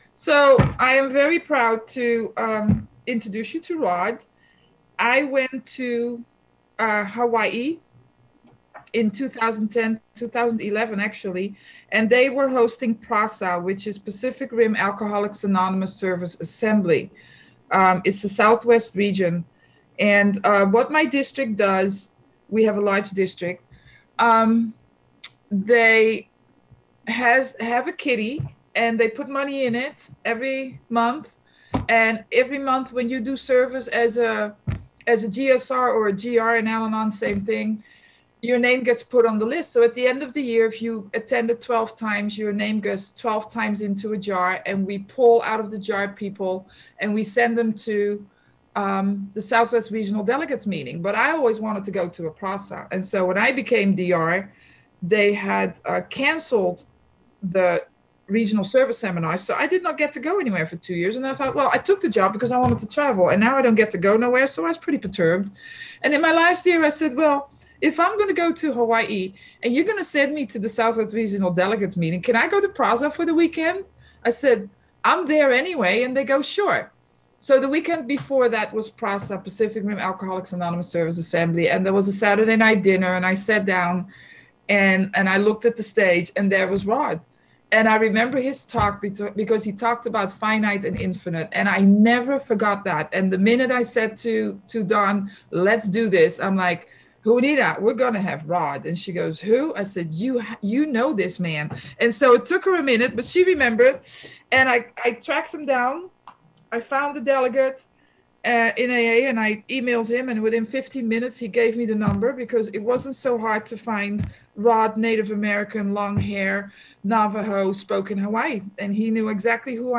Encore Audio Archives - 12 Step Recovery American Indian Conference - AWB Roundup Oct 17-18